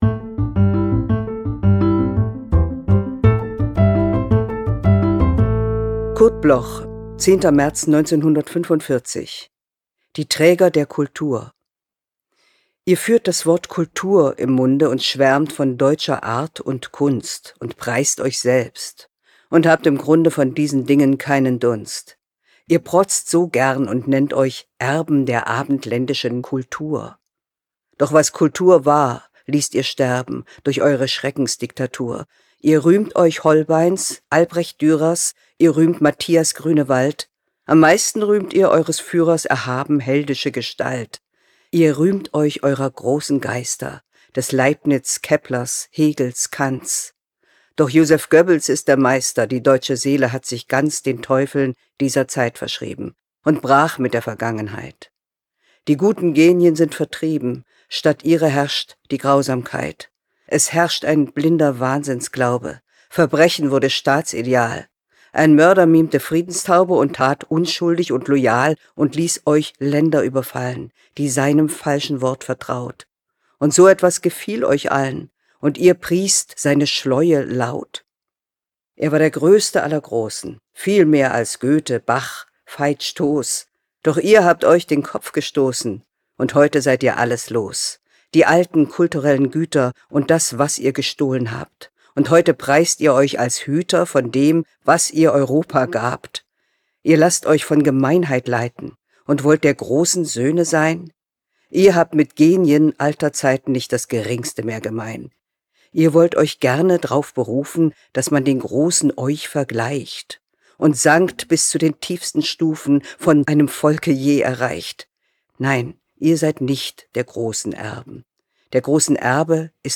Bearbeitung und Musik: Kristen & Schmidt, Wiesbaden
C.Harfouch-Die-Traeger-der-Kultur_mit-Musik.m4a